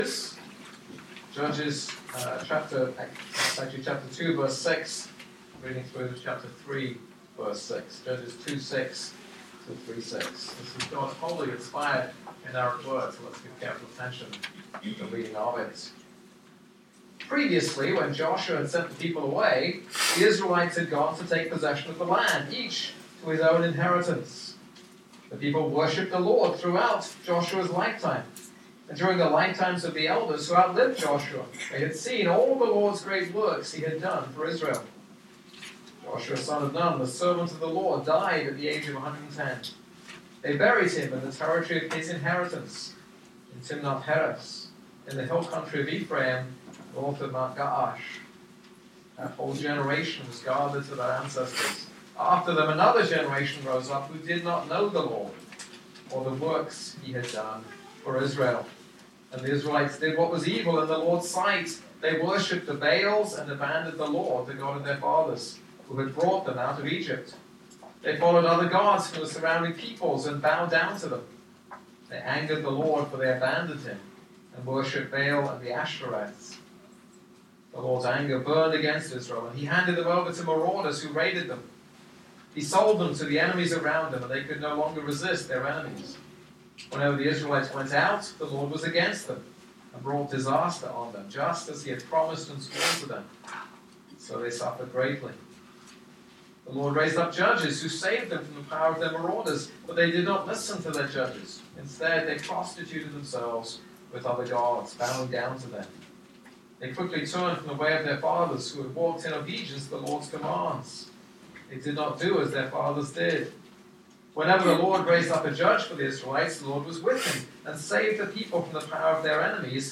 This is a sermon on Judges 2:6-3:6.